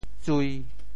「睢」字用潮州話怎麼說？
睢 部首拼音 部首 目 总笔划 13 部外笔划 8 普通话 huī suī 潮州发音 潮州 zui1 文 中文解释 睢 <形> 仰視的樣子 [looking-upward] 睢,仰目也,從目,隹聲。
tsui1.mp3